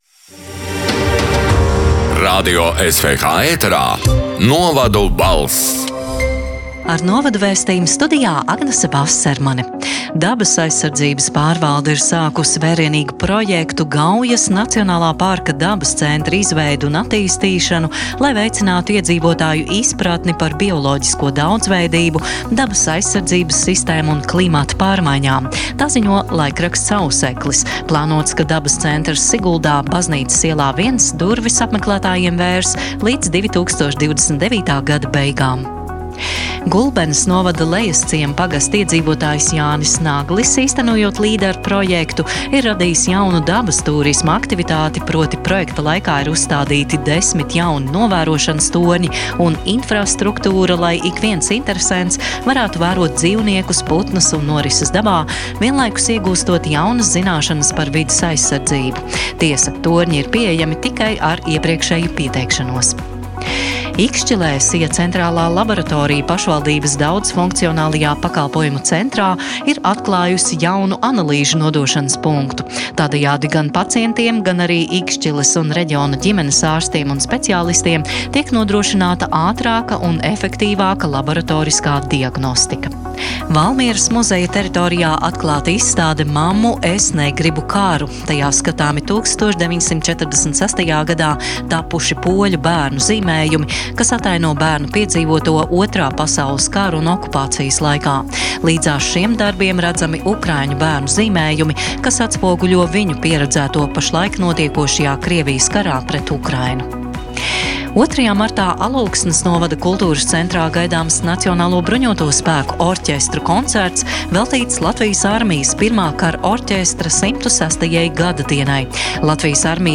“Novadu balss” 26. februāra ziņu raidījuma ieraksts: